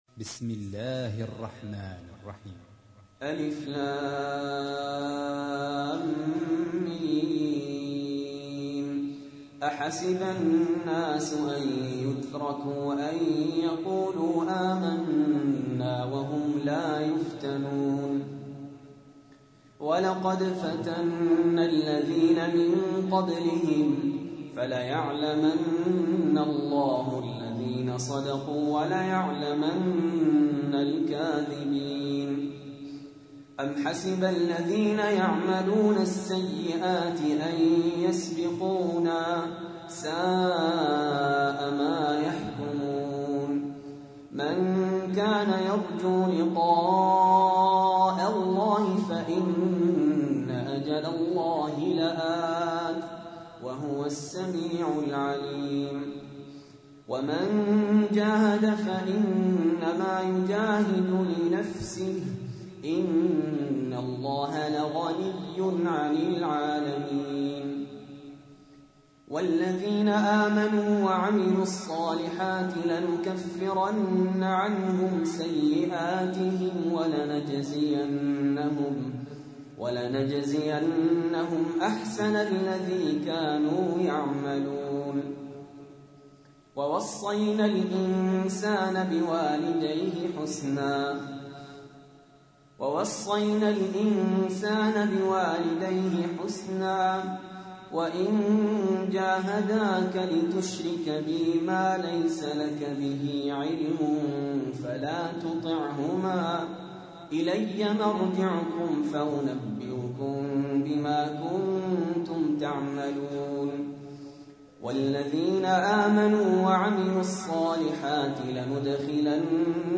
تحميل : 29. سورة العنكبوت / القارئ سهل ياسين / القرآن الكريم / موقع يا حسين